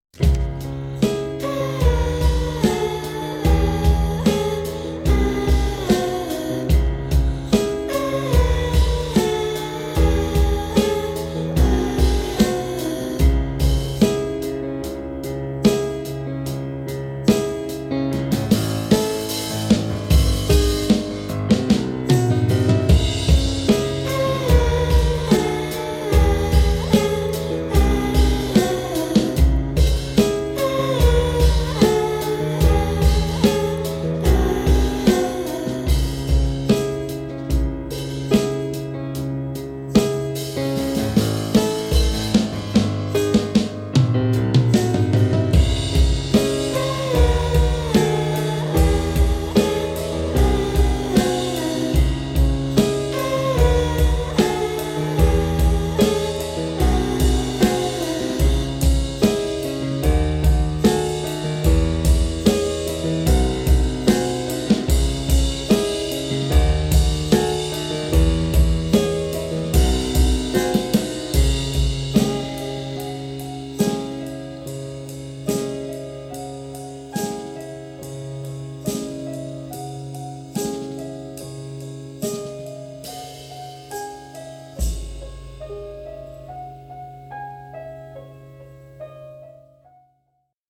Сегодня во сне мозг одарил хоральной партией, с
вумен вокалом, но некой другой, более качественной и мощной вещью.